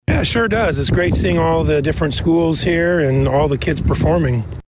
spoke with us while he was outside for a breath of fresh air.